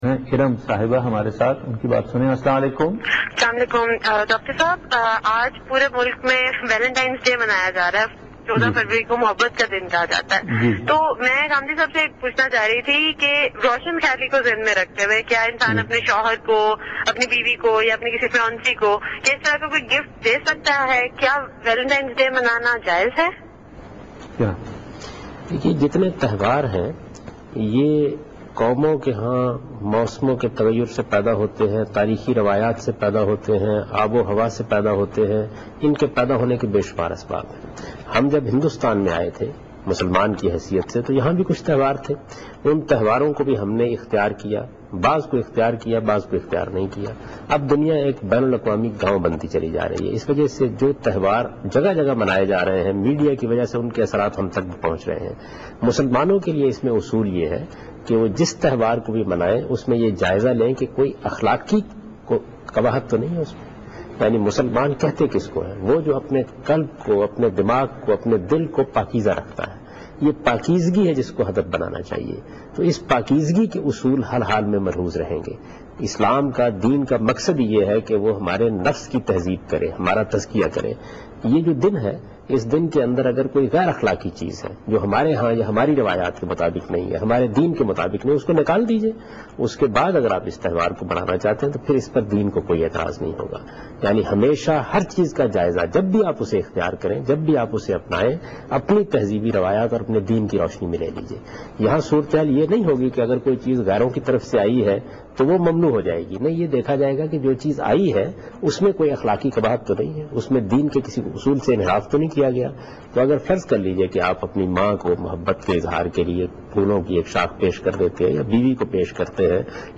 Category: TV Programs / Dunya News / Questions_Answers /
A short clip from a television show aired on Dunya Tv